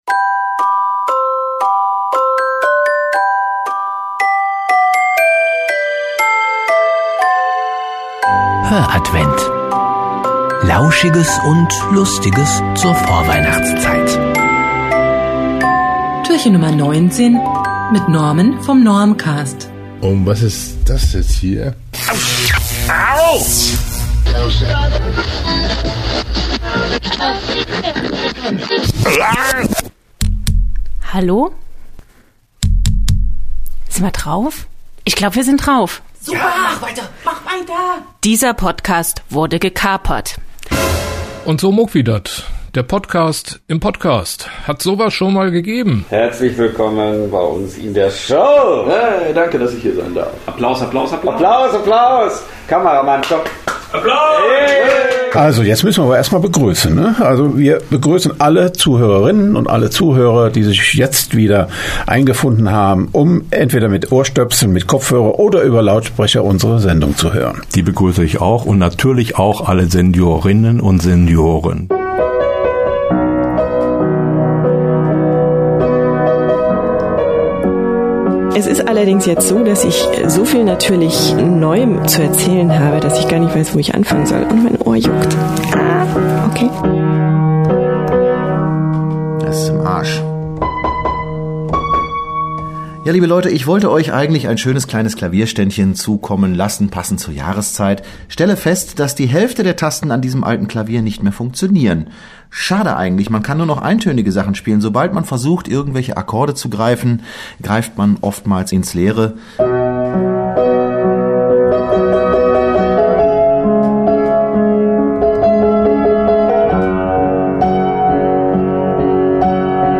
…und hier noch ein kleiner Dreiminueter mit einigen Gaststimmen zum Fest.